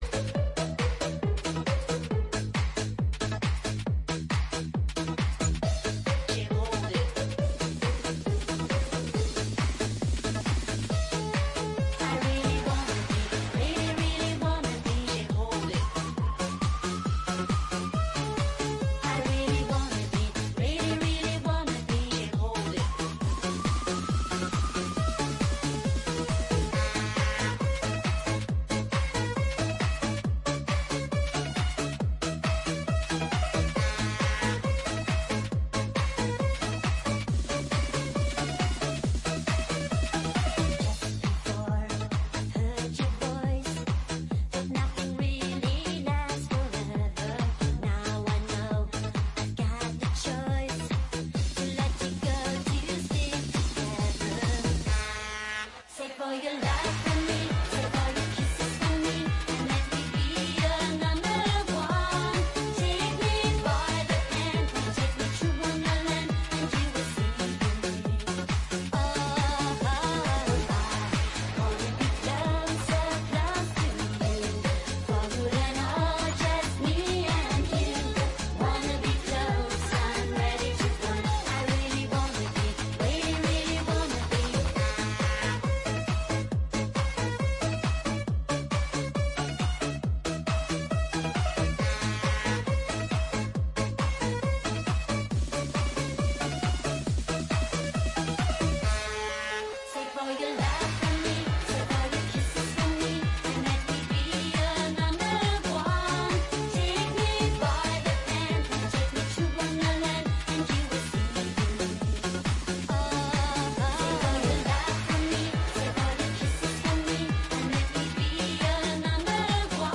Italo Dance